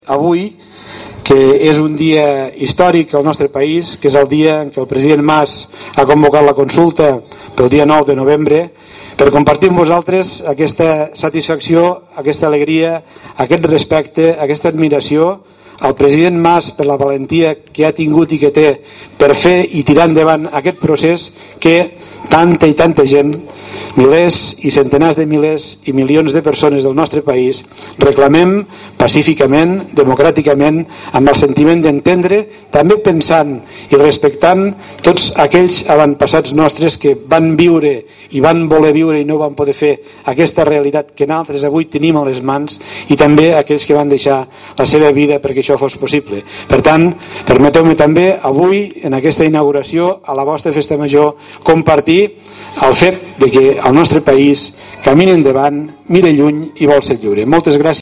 Així ho ha manifestat Reñé en l’acte d’inauguració de l’equipament del safareig municipal de Vallfogona de Balaguer.